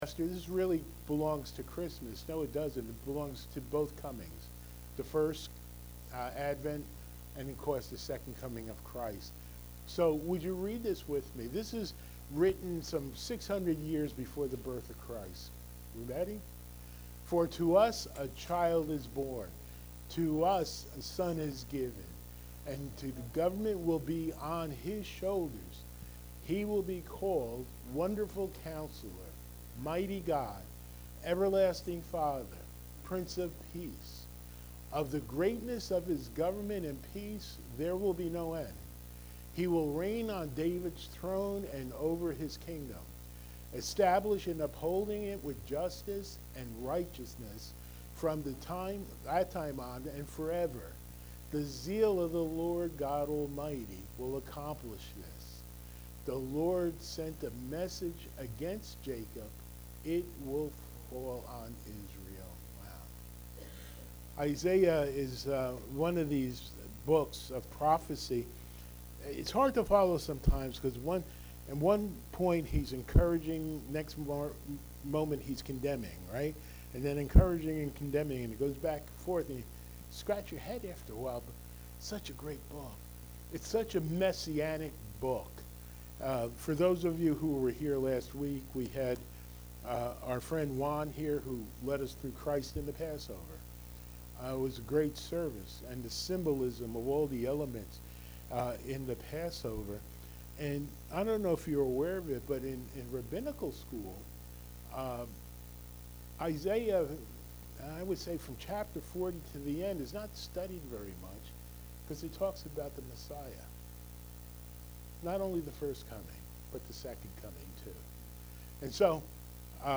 Series: Sunday Morning Worship Service